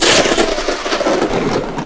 Thunder Sound Effect
Download a high-quality thunder sound effect.
thunder.mp3